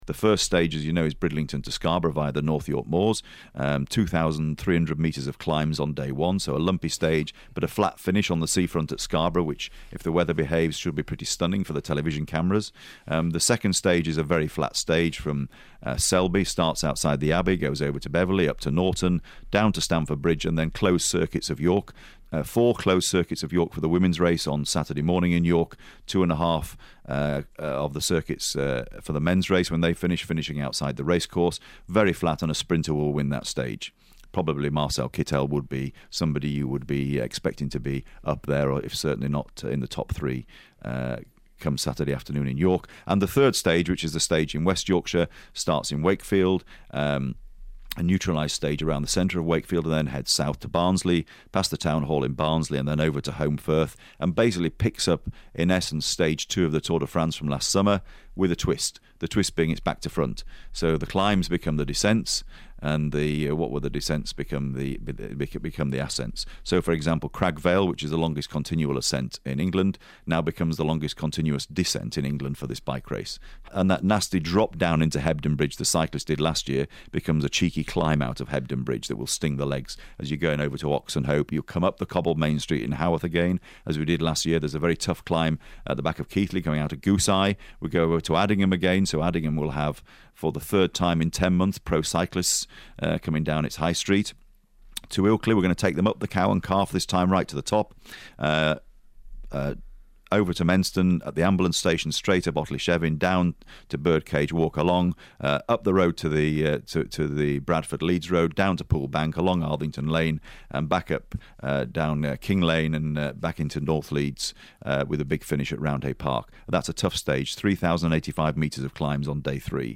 and he barely takes a breath!